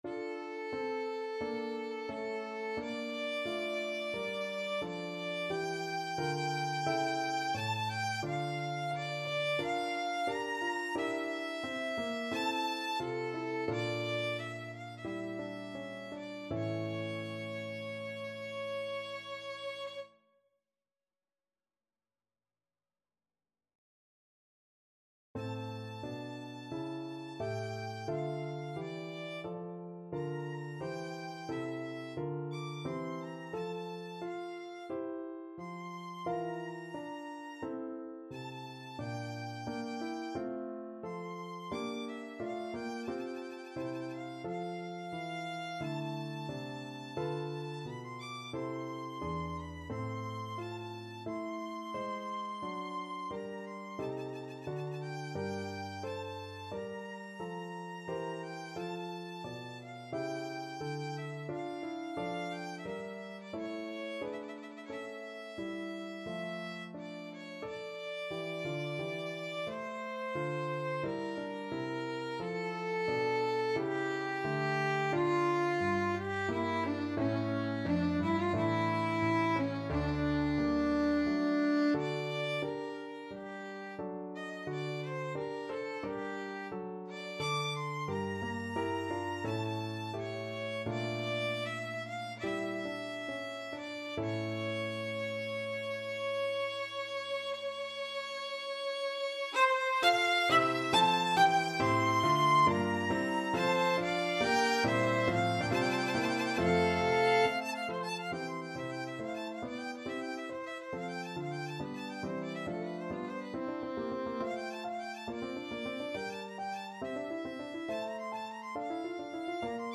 Adagio =88
Classical (View more Classical Violin Music)